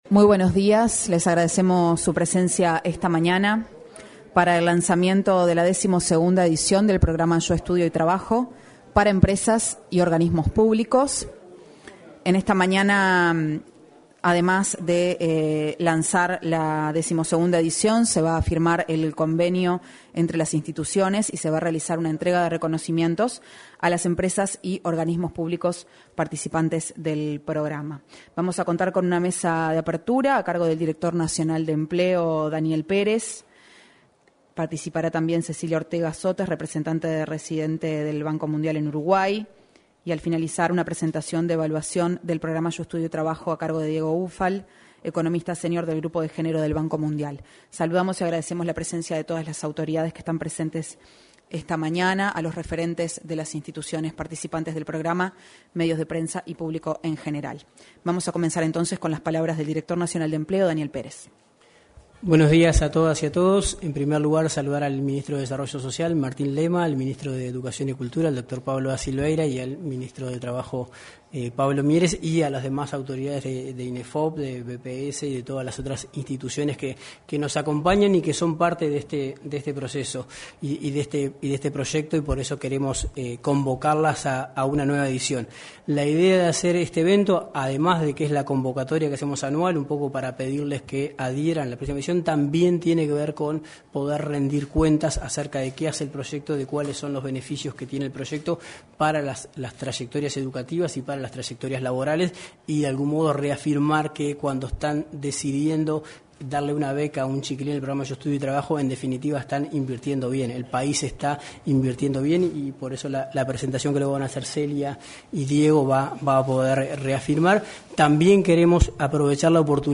Presentación de la 12.ª edición del programa Yo Estudio y Trabajo 21/06/2023 Compartir Facebook X Copiar enlace WhatsApp LinkedIn Este miércoles 21, se realizó la presentación de un estudio de evaluación del programa Yo Estudio y Trabajo, realizado por el Banco Mundial y la Unidad de Estadística del Ministerio de Trabajo. Asimismo, se entregó un reconocimiento a varias instituciones por su compromiso con la iniciativa.